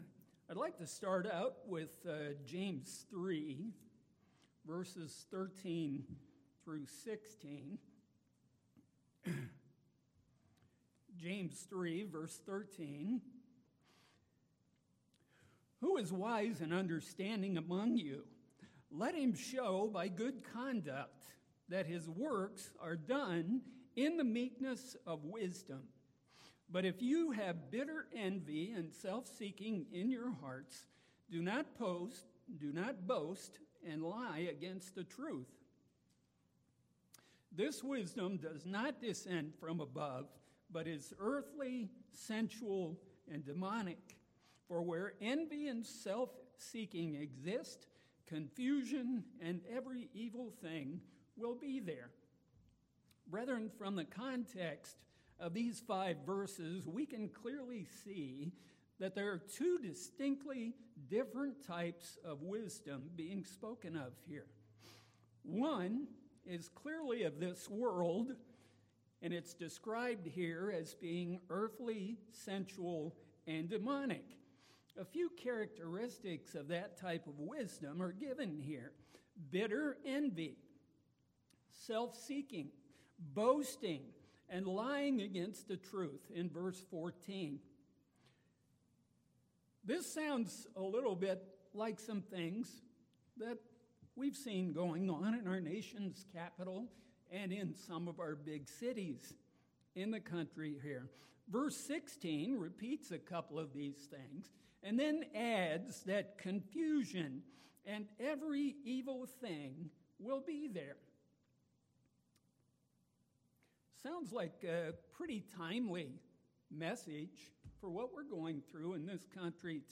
Sermons
Given in Mansfield, OH